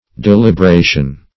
Delibration \Del`i*bra"tion\, n. The act of stripping off the bark.